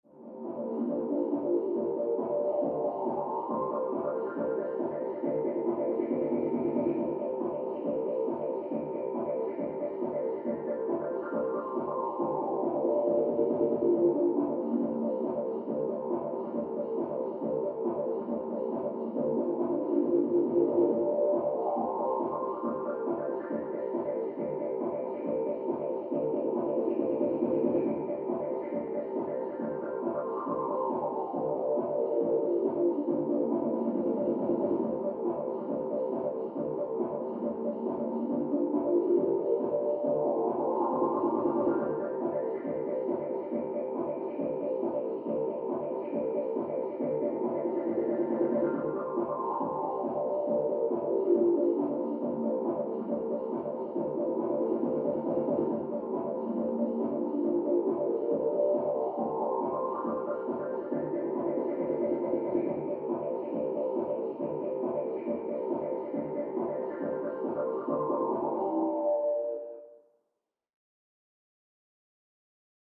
Music; Electronic Dance Beat, From Next Room.